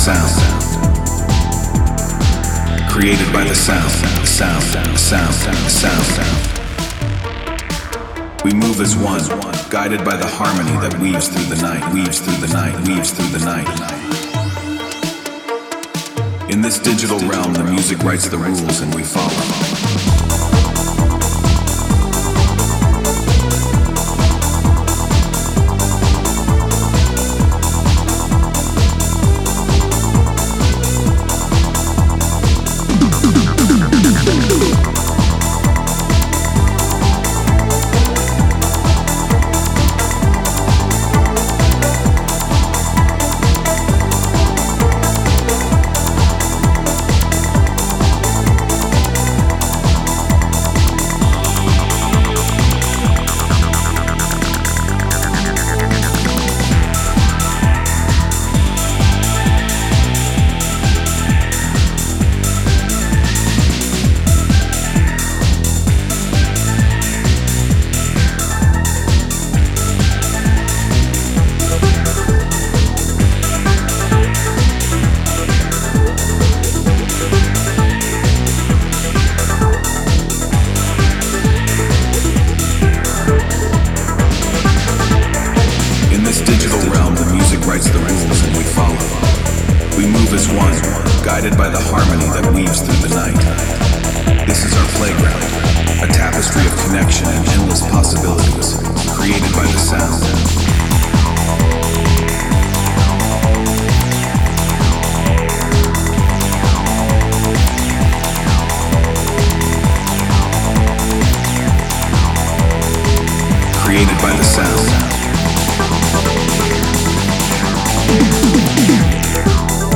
With a fusion of electronic rhythms and immersive textures